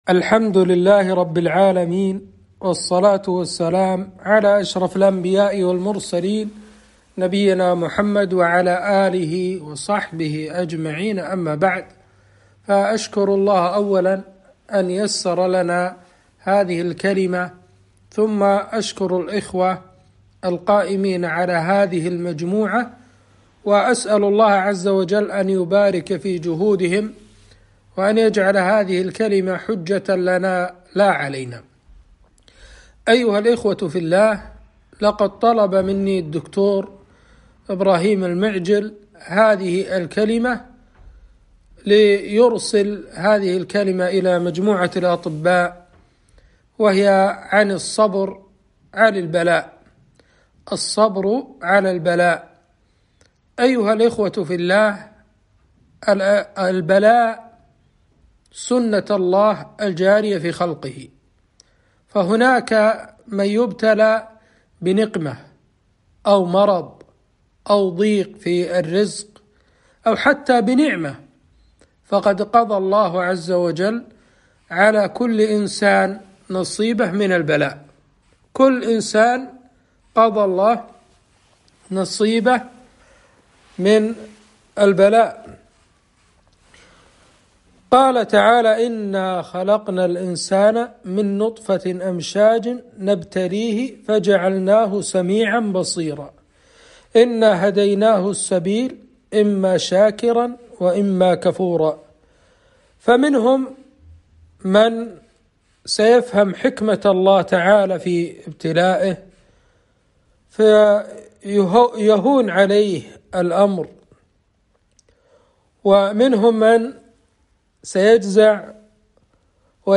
محاضرة - الصبر على البلاء